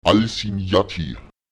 Lautsprecher al’simnate [ÇalsiÈøate] die Galaxie